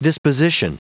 Prononciation du mot disposition en anglais (fichier audio)